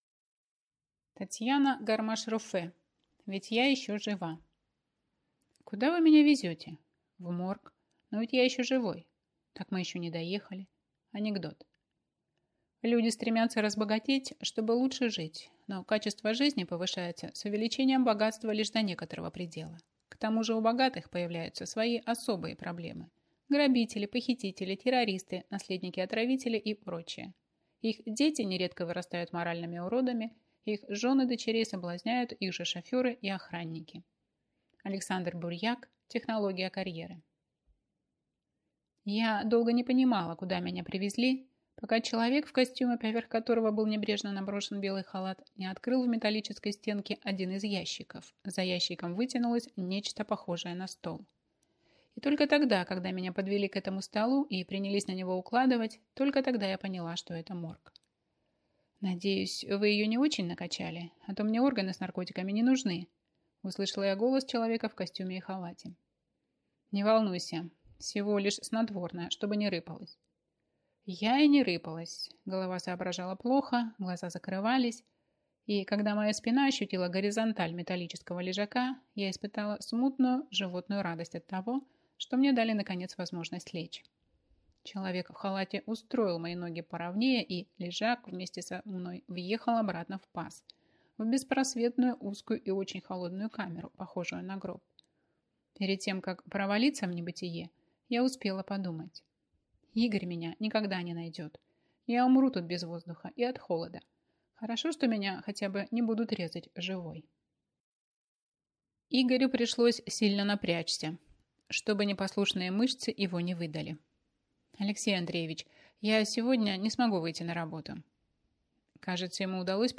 Аудиокнига Ведь я еще жива - купить, скачать и слушать онлайн | КнигоПоиск
Аудиокнига «Ведь я еще жива» в интернет-магазине КнигоПоиск ✅ в аудиоформате ✅ Скачать Ведь я еще жива в mp3 или слушать онлайн